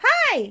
toadette_hello.ogg